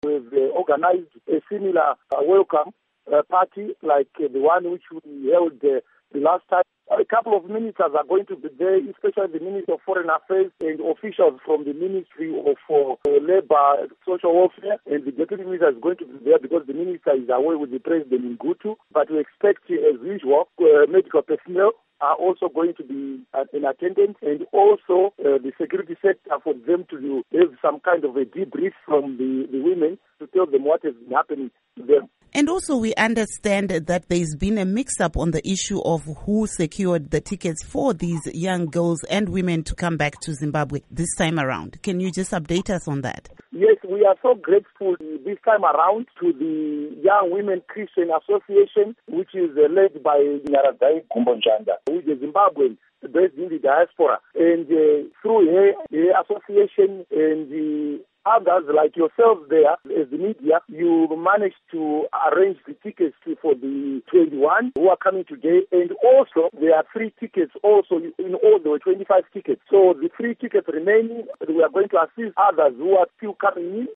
Interview WIth Kindness Paradza